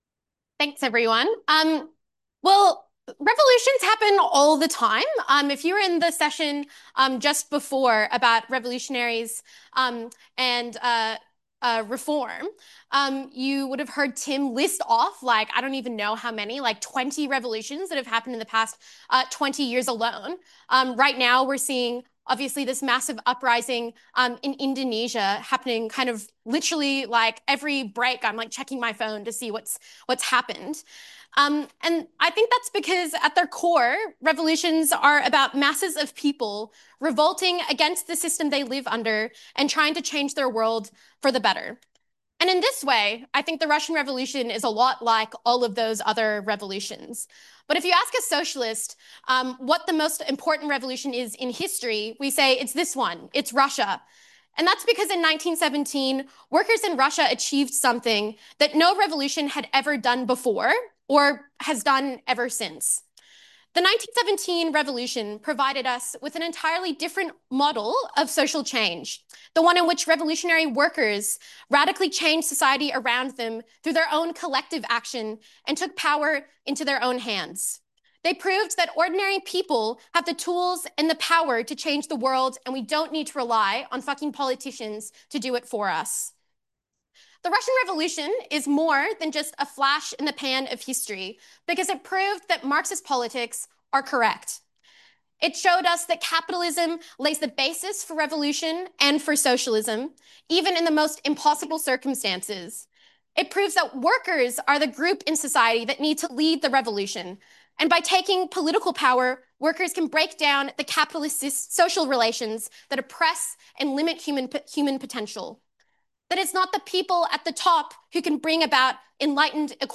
when workers took power Socialism 2025 (Brisbane) Play talk Download Marx Talks is a project of Socialist Alternative